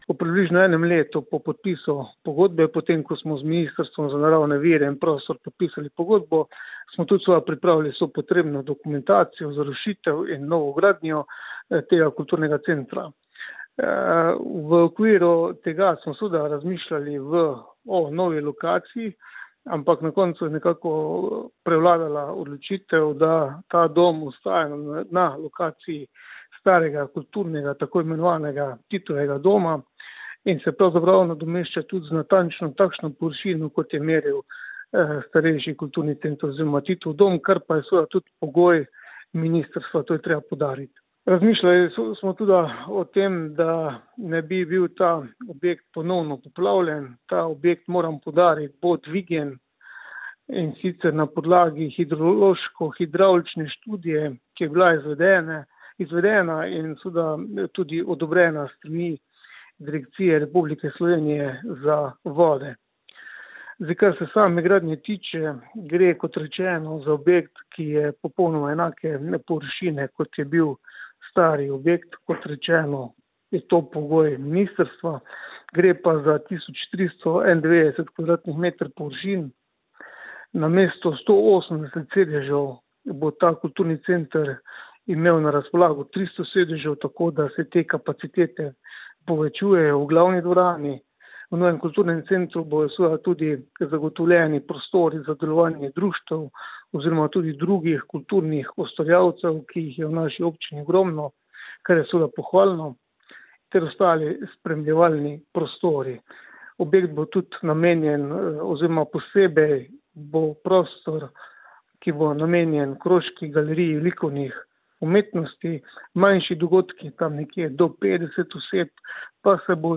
Prejšnji teden je na Ravnah potekala predstavitev popoplavne sanacije in izvedbi projekta Kulturni center. Kako daleč je projekt pa podžupan občine Ravne na Koroškem, Andrej Erjavec: